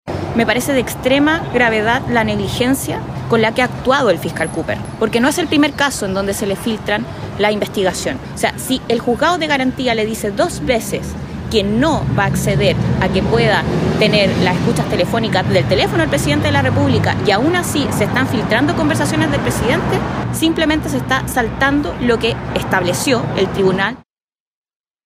En ese sentido, la diputada de la colectividad, Gael Yeomans, acusó una “extrema negligencia” en el actuar del fiscal Cooper, apuntando a las constantes filtraciones desde el Ministerio Público.